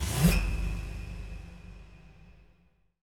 SI2 PIANO06R.wav